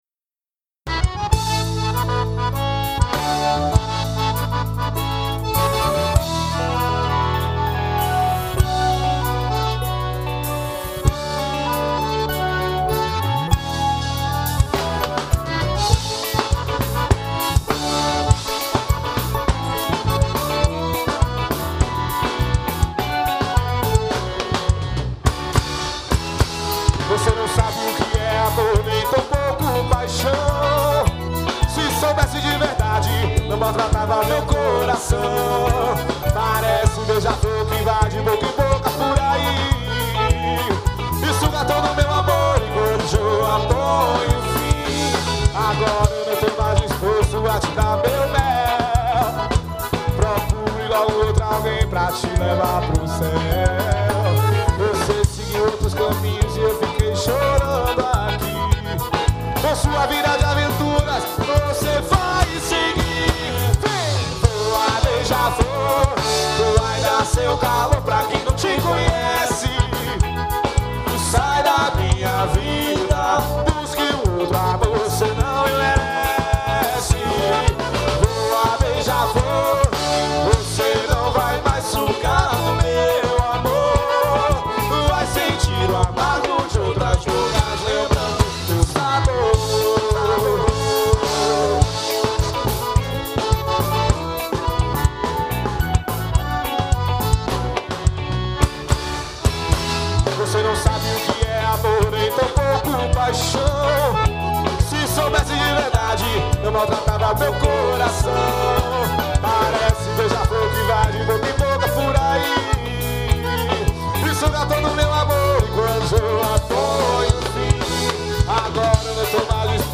Composição: Ao Vivo.